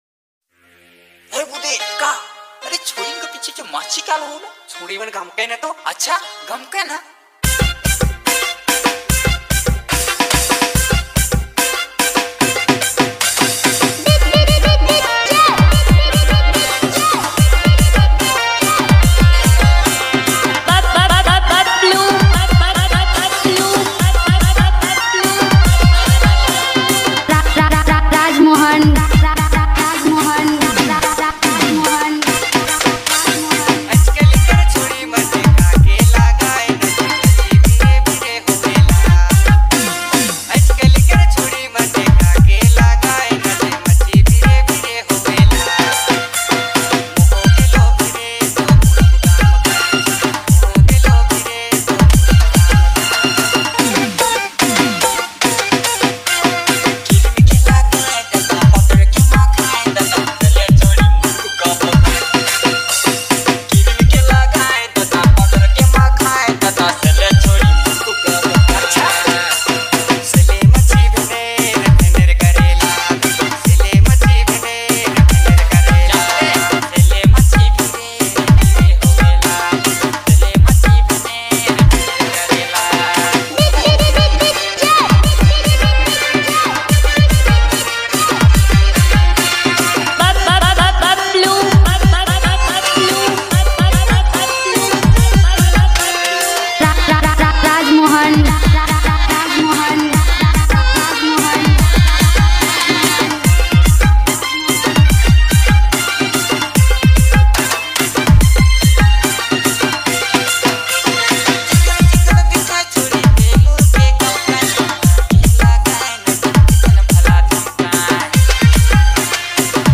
New Latest Nagpuri Song